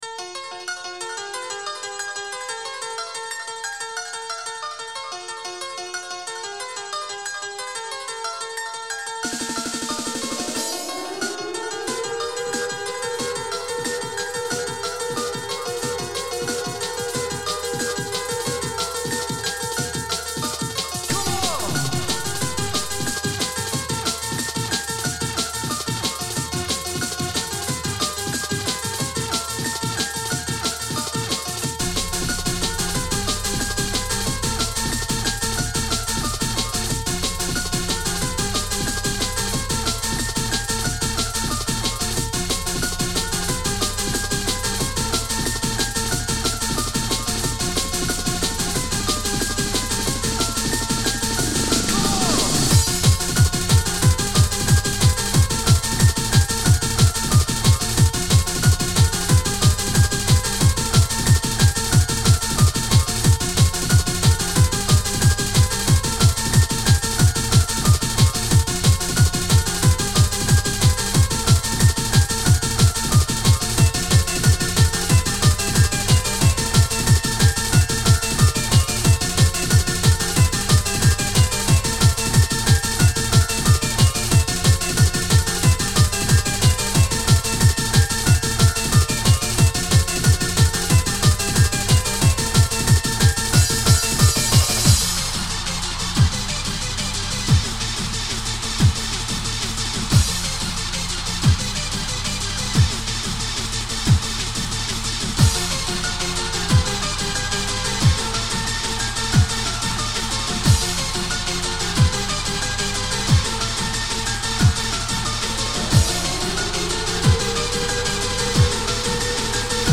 alarma remix
• Jakość: 44kHz, Stereo